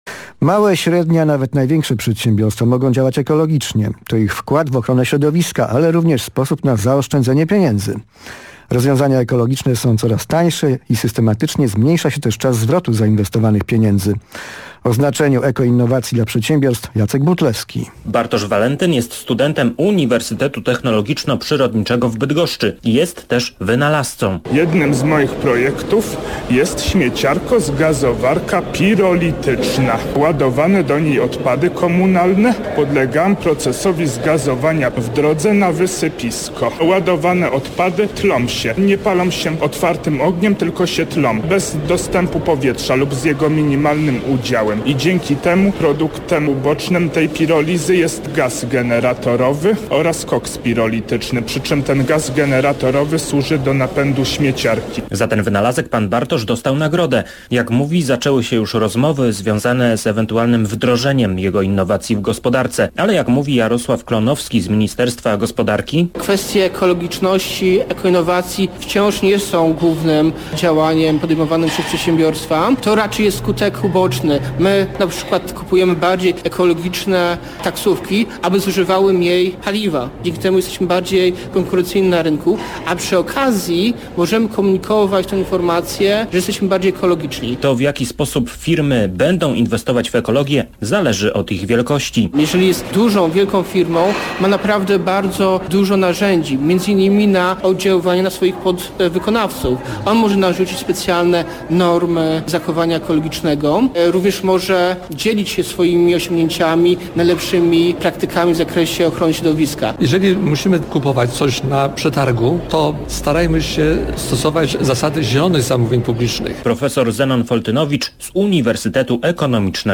Uczestnicy Konferencji EVENT o EKOinnowacjach rozmawiają na antenie Programu Pierwszego o wprowadzaniu ekoinnowacji w przedsiębiorstwach.